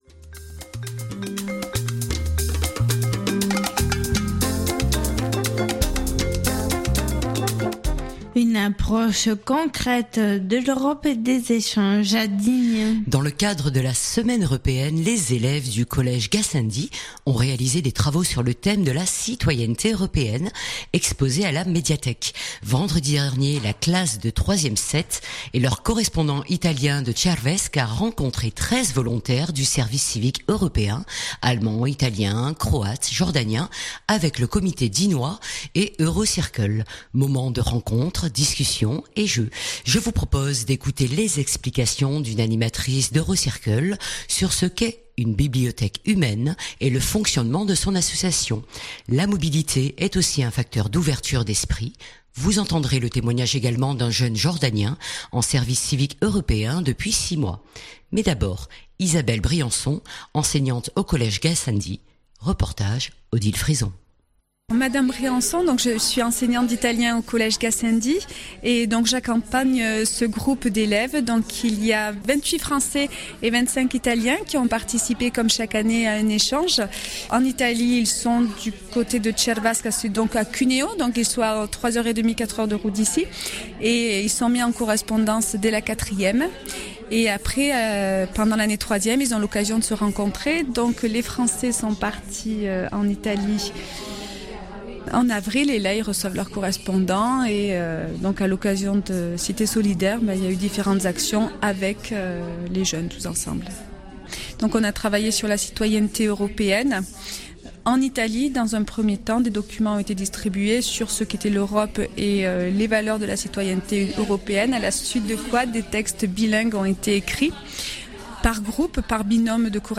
Je vous propose d’écouter les explications d’une animatrice d’Eurocircle, sur ce qu’est une bibliothèque humaine et le fonctionnement de son association. La mobilité est aussi un facteur d’ouverture d’esprit. Vous entendrez le témoignage d’un jeune Jordanien, en service civique européen depuis 6 mois.